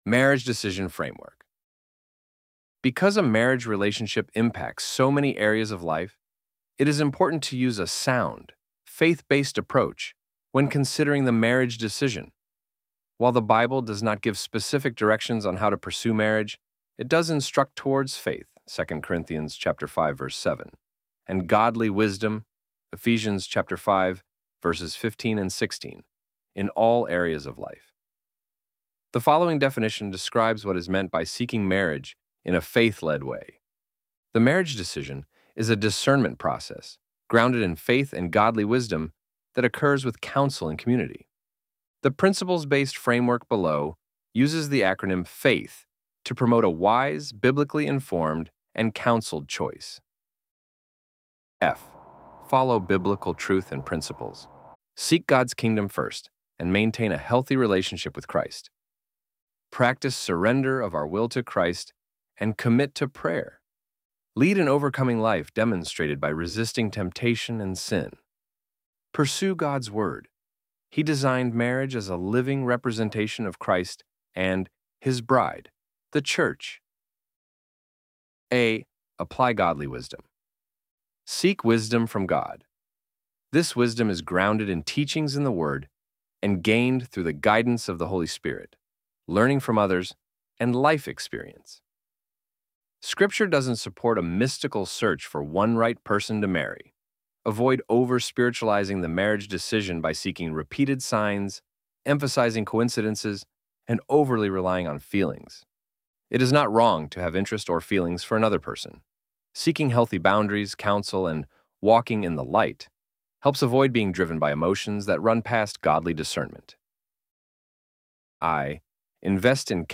ElevenLabs_Marriage_Decision_Framework_-_CM.mp3